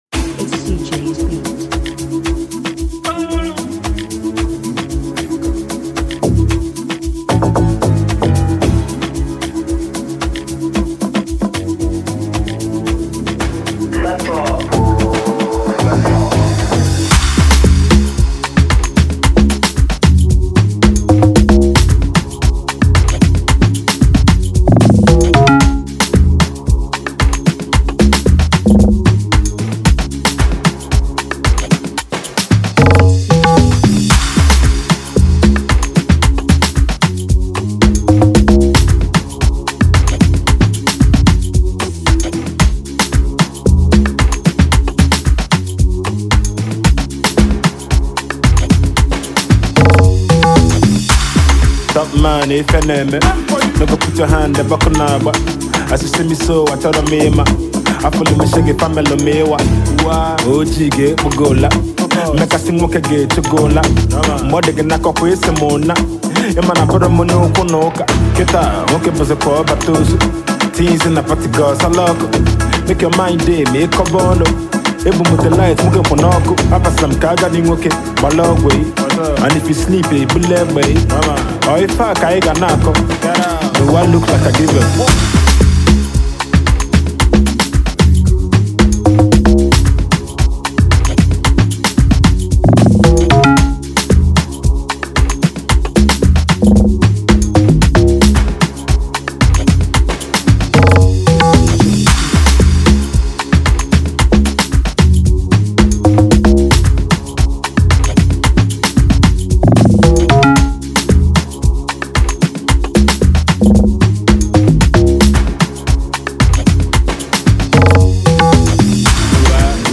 it’s fire instrumental that has blazing sounds and kicks.